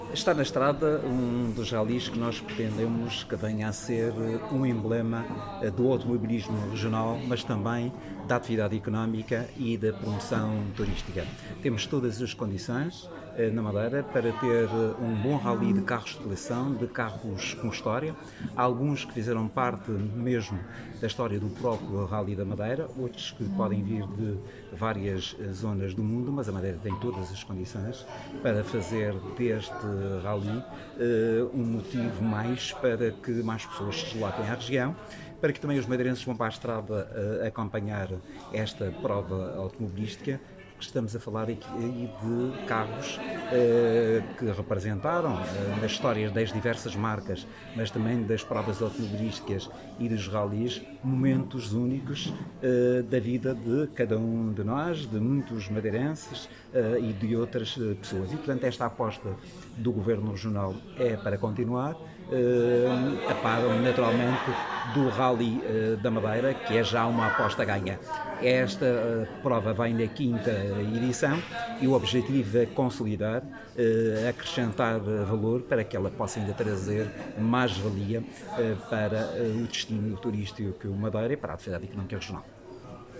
Durante a cerimónia, o governante reafirmou a ambição do Executivo em projetar o evento para um novo patamar.
Secretário da Economia_JMR_RallyLeggend (2).mp3